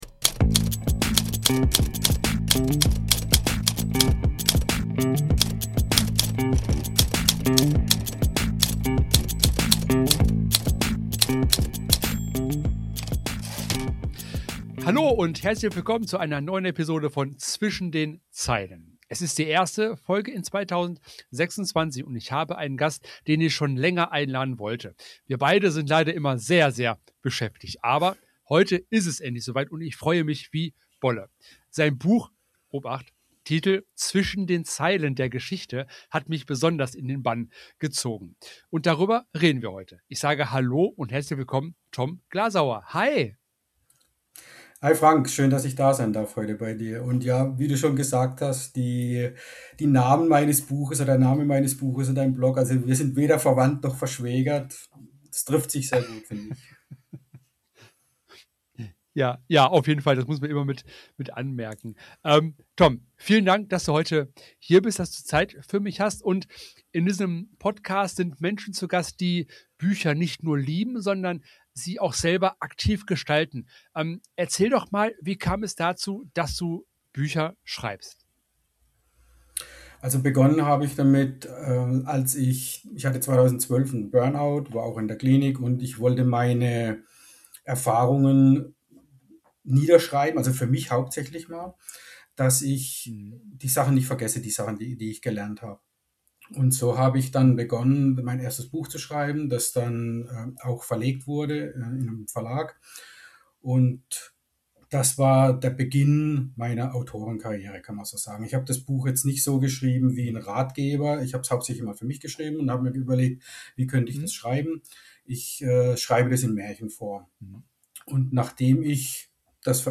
Das bedeutet für euch: Füße hochlegen und einem angenehmen Interview lauschen.